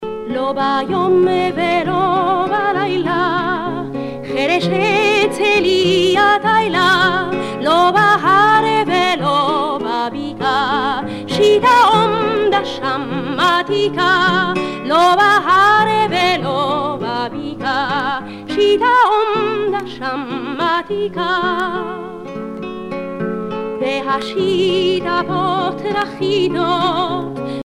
Prières et chants religieux
Pièce musicale éditée